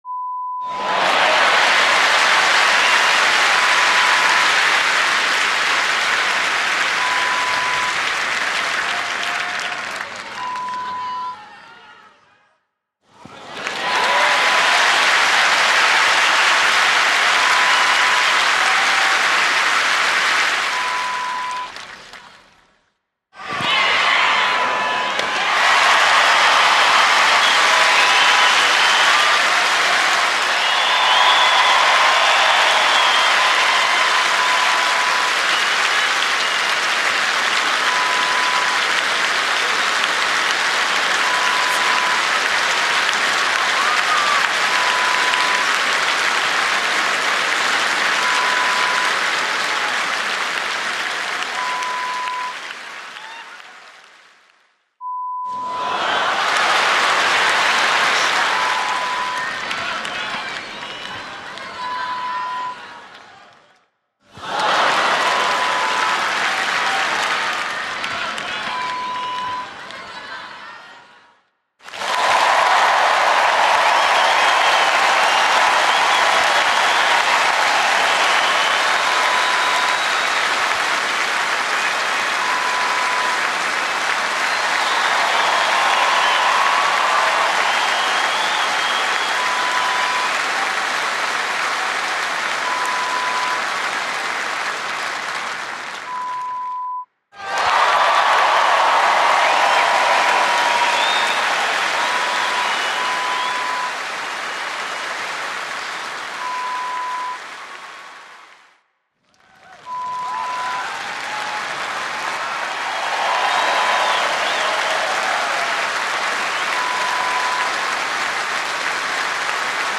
Wimbledon Applause Collection (sound Effect)
(3:00) TENNIS CROWD: A packed center court at Wimbledon - 14000 people clapping and cheering during the mens final.
Wimbledon-Applause-Collection--Lo-Fi-preview-.mp3